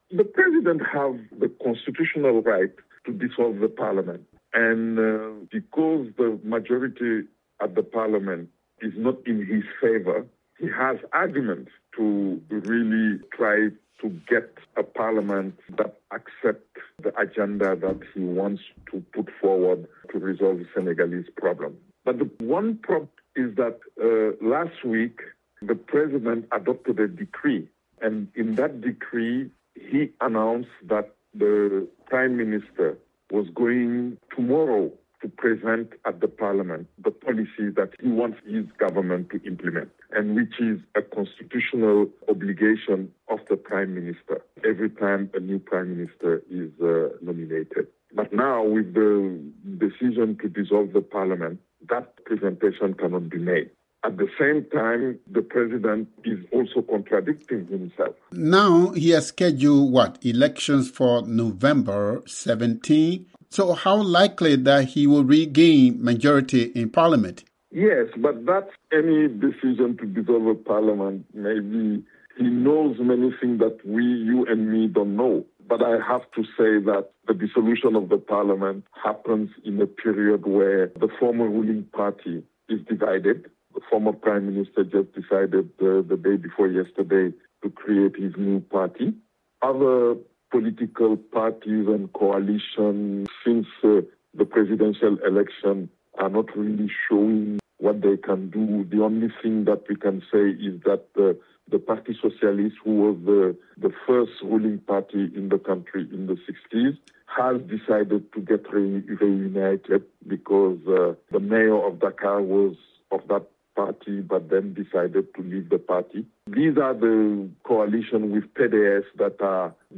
Senegalese political analyst